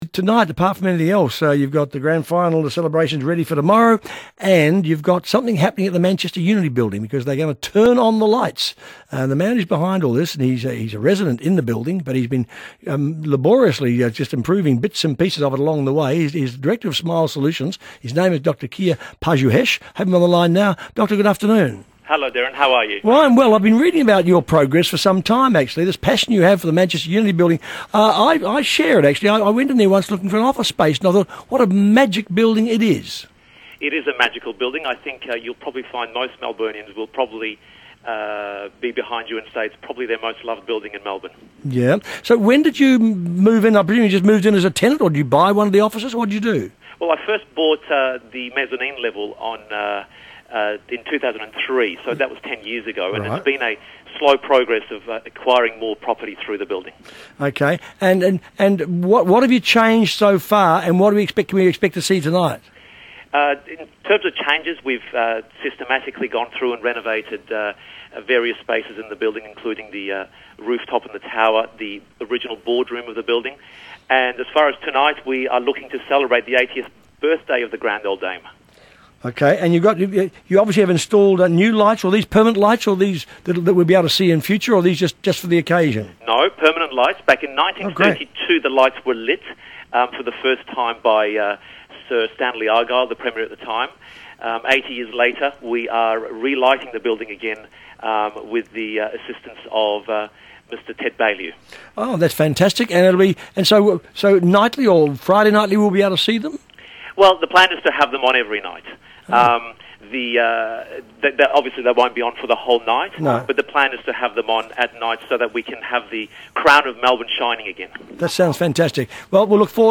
Smile Solutions interview with Denis Walter on 3AW Radio.